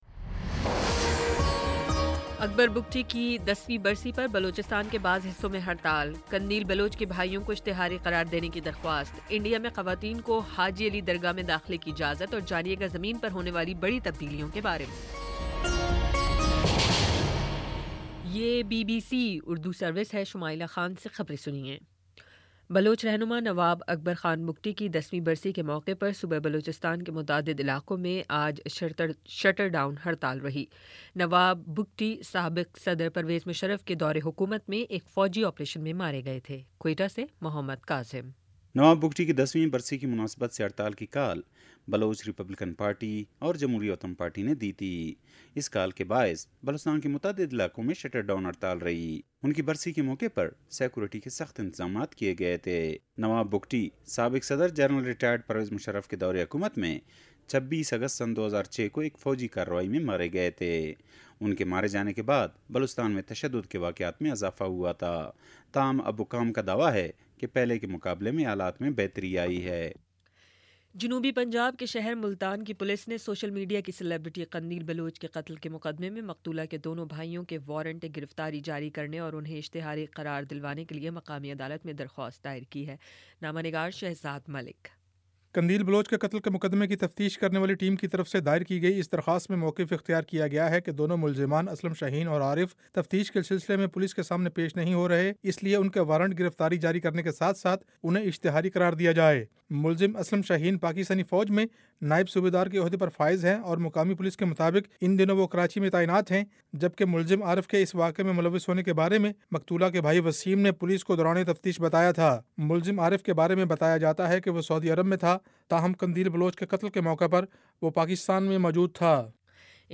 اگست 26 : شام چھ بجے کا نیوز بُلیٹن